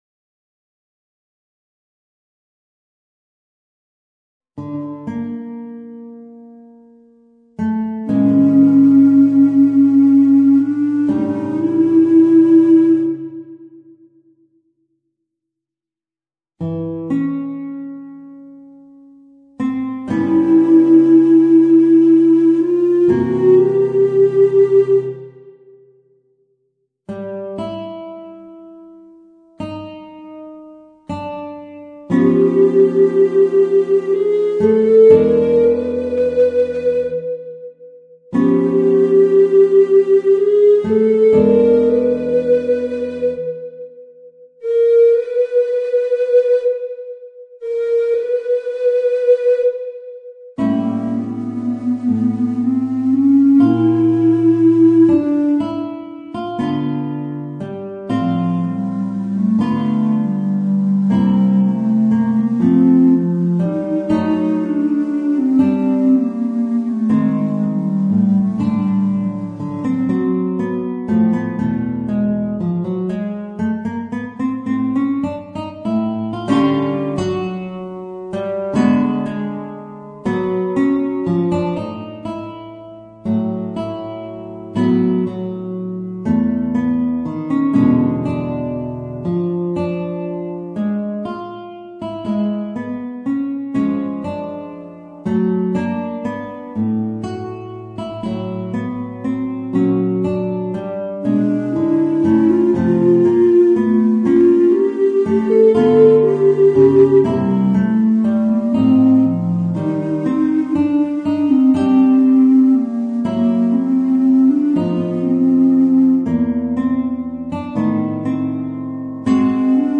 Voicing: Guitar and Bass Recorder